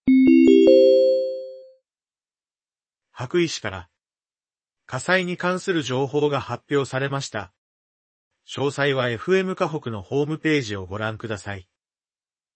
エフエムかほく 「こんにちは高橋しげのりです」 木曜11時～生放送、再放 送 金曜7時30分～、日曜12時30分～ 放送しています。